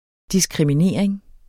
Udtale [ disgʁimiˈneˀʁeŋ ]